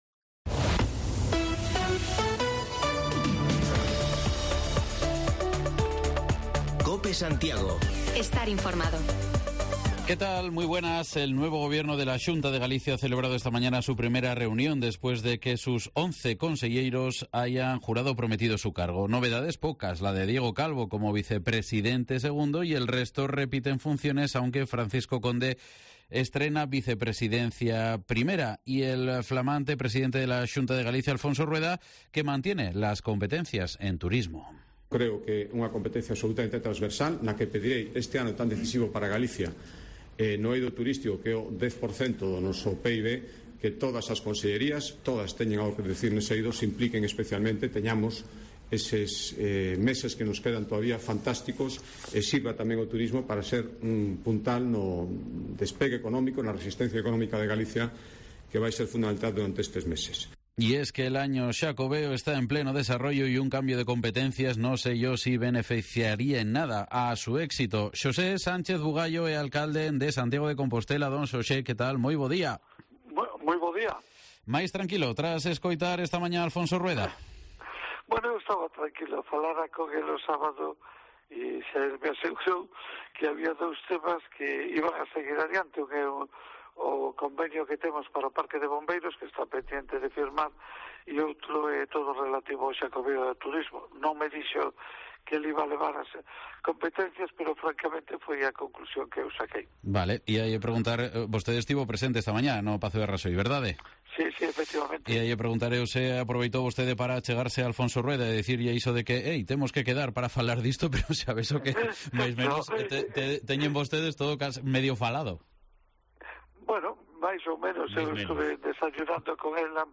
AUDIO: Repasamos la actualidad municipal en Santiago en entrevista con el alcalde Sánchez Bugallo